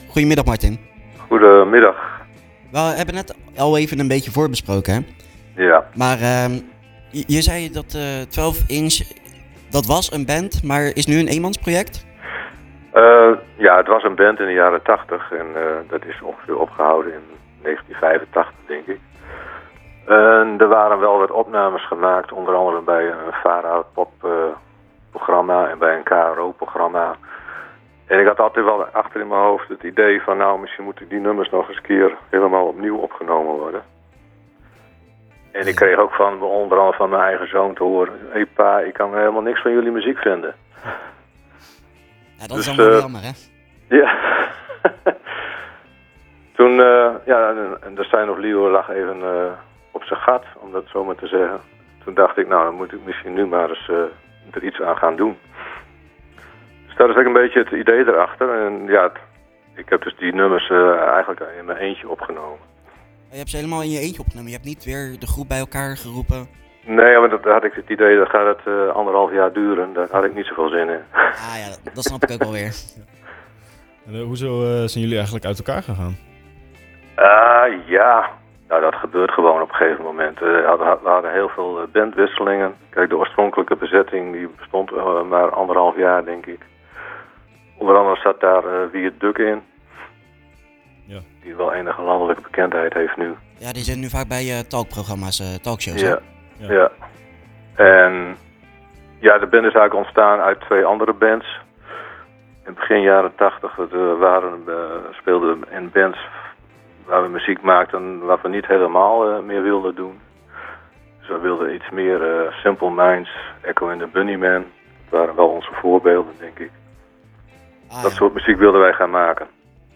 Tijdens de wekelijkse uitzending van Zwaardvis